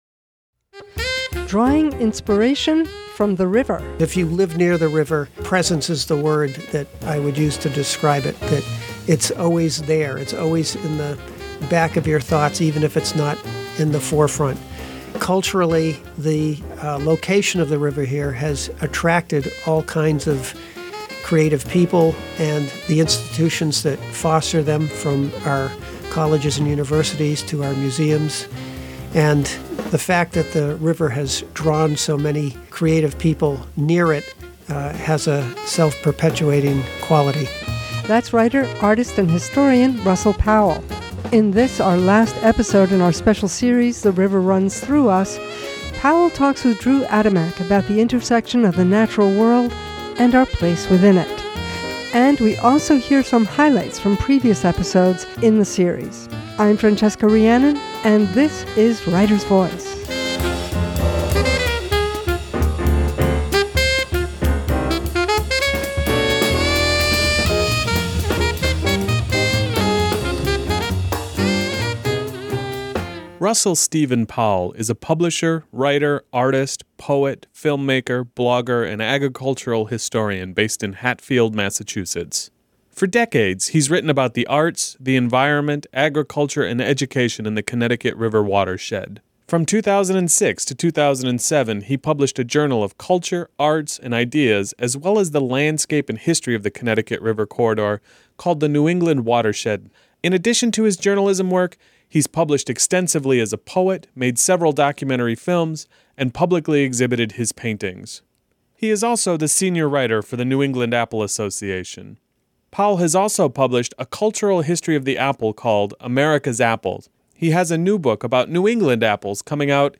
We talk with Brad Gooch about his acclaimed new biography of Keith Haring, RADIANT: The Life and Line of Keith Haring. Then we listen back to a clip of Gooch talking with us in 2017 about his biography, Rumi’s Secret: The Life of the Sufi Poet of Love.